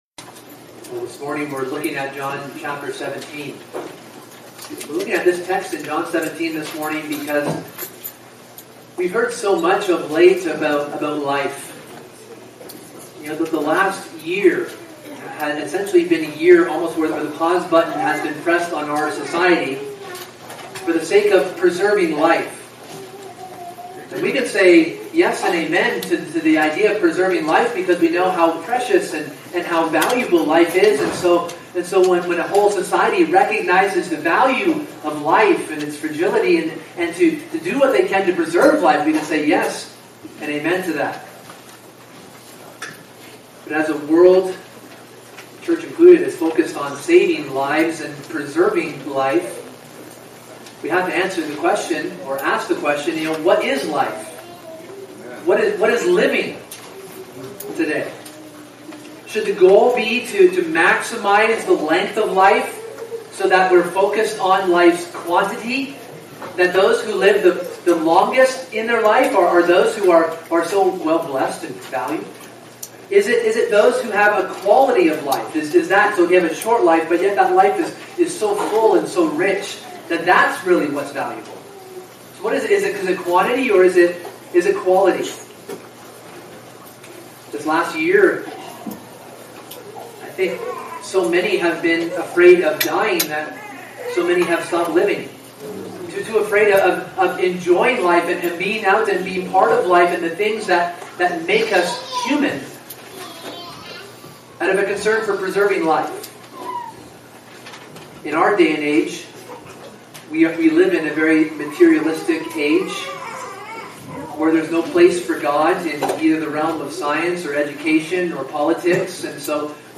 * We apologize, the audio quality on this recording is poor.